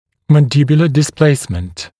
[ˌmæn’dɪbjulə dɪs’pleɪsmənt][ˌмэн’дибйулэ дис’плэйсмэнт]смещение нижней челюсти